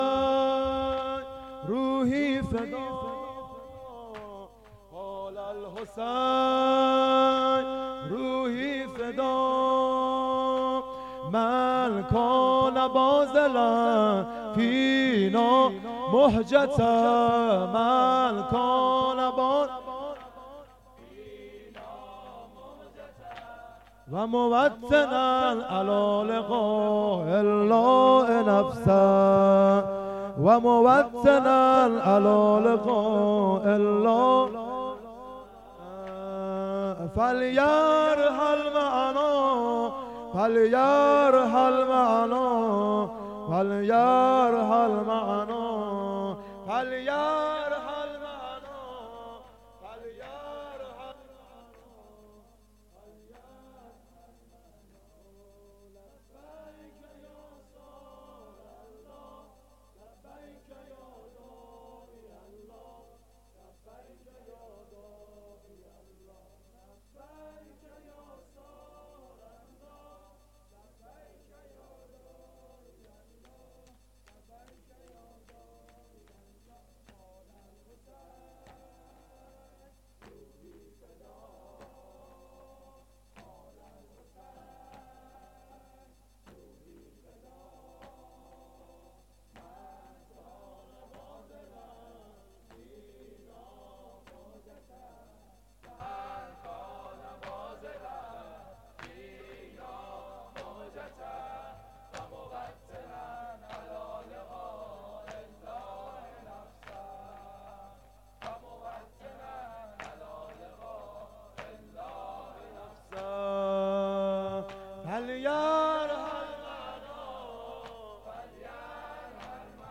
مداحی
شعر پایانی : قال الحسین روحی فداه...